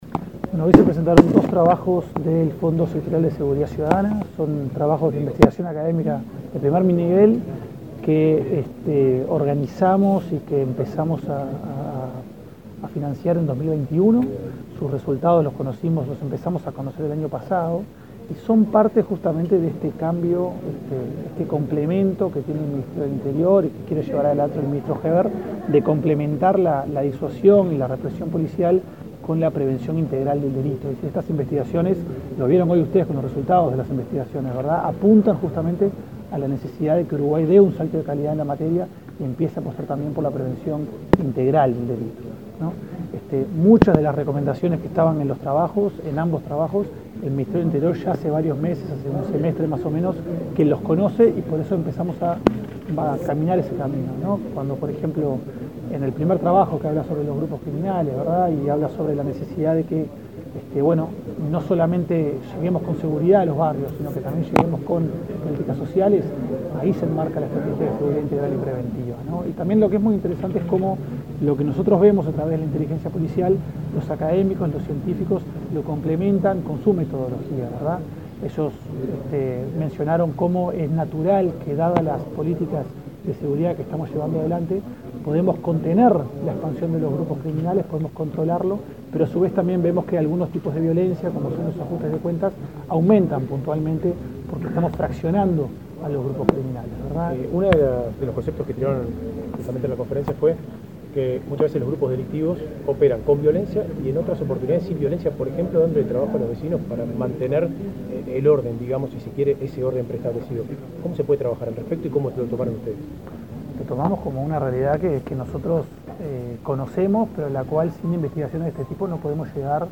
Declaraciones a la prensa del coordinador de Estrategias Focalizadas en Prevención del Delito
El coordinador de Estrategias Focalizadas en Prevención del Delito, del Ministerio del Interior, Diego Sanjurjo, dialogó con la prensa luego de